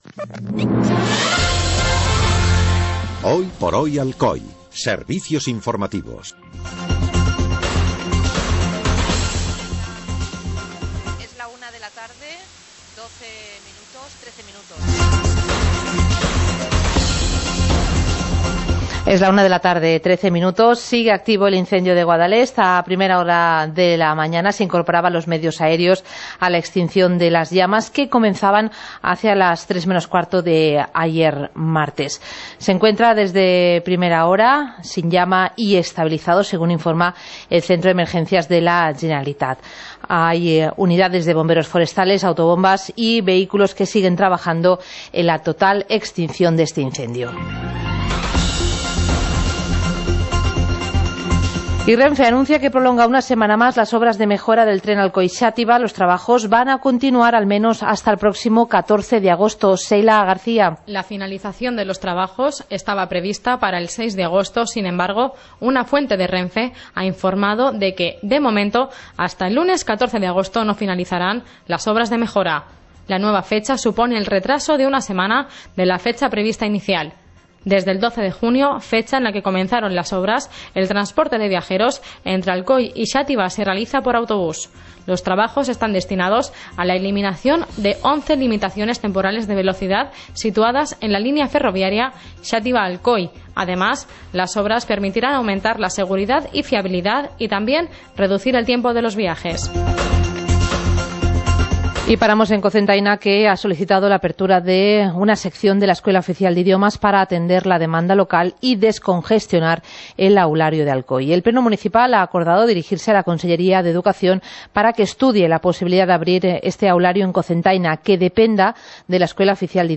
Informativo comarcal - miércoles, 26 de julio de 2017